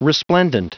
Prononciation du mot resplendent en anglais (fichier audio)
Prononciation du mot : resplendent